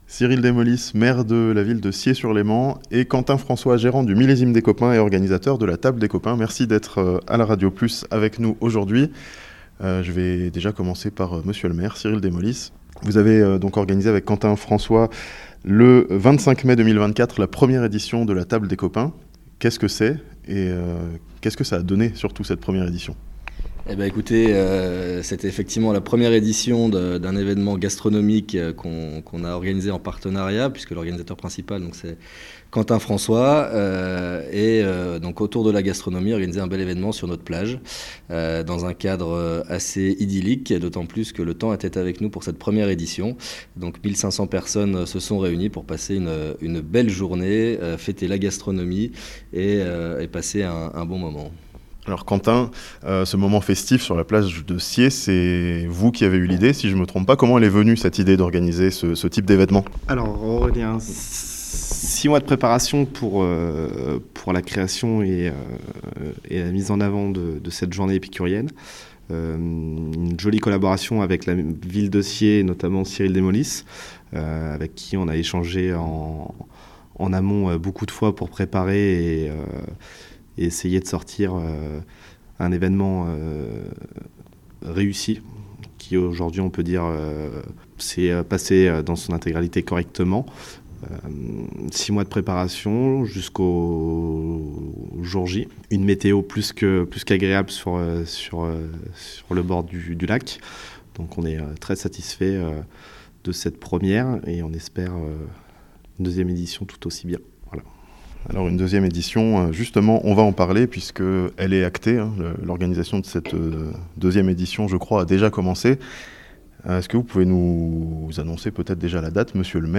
La "Table des Copains" reviendra à Sciez, dans le Chablais, en 2025 (interview)